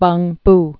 (bŭngb) also Peng·pu (pŭngp)